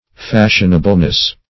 Fashionableness \Fash"ion*a*ble*ness\, n.